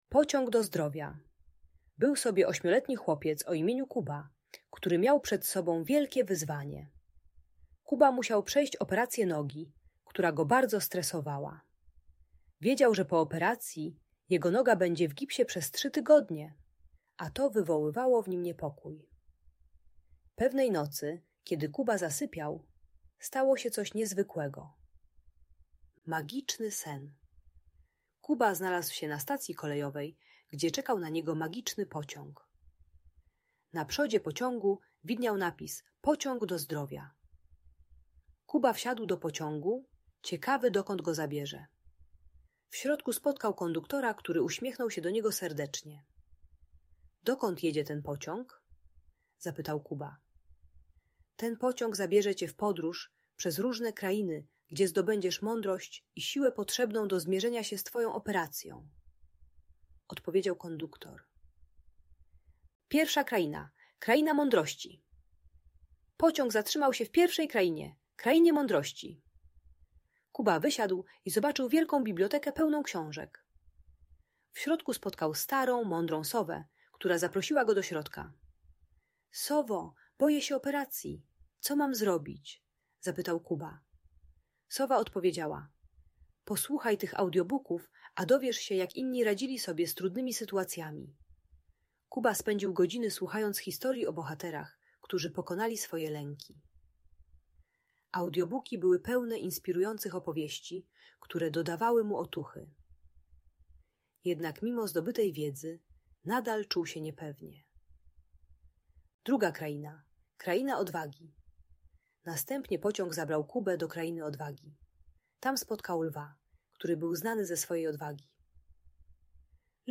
Magiczna podróż Kuby - Lęk wycofanie | Audiobajka